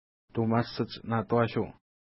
ID: 165 Longitude: -62.1971 Latitude: 55.7605 Pronunciation: tu:ma:səts-na:twa:ʃu: Translation: Thomas' Children Lake Feature: lake Explanation: The person refered to in this place name has not been identified.